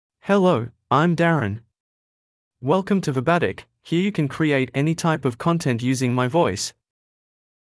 MaleEnglish (Australia)
Darren is a male AI voice for English (Australia).
Voice sample
Darren delivers clear pronunciation with authentic Australia English intonation, making your content sound professionally produced.